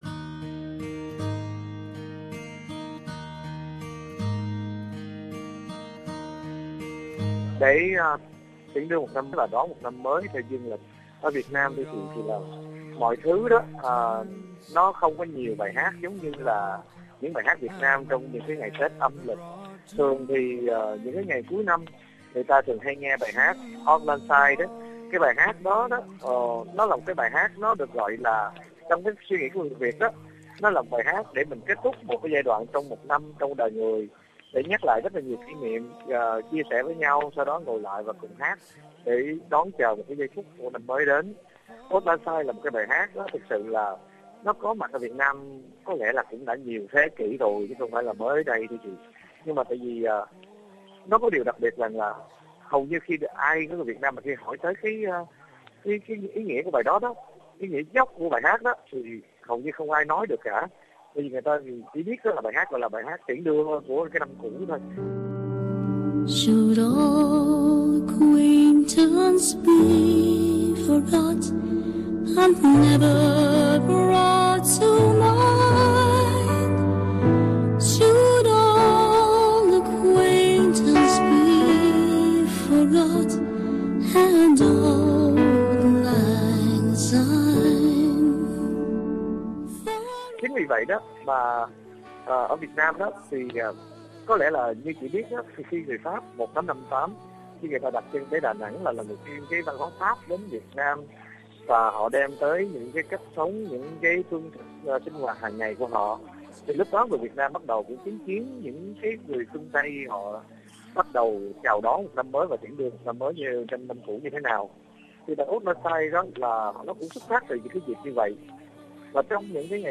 Trò chuyện cuối năm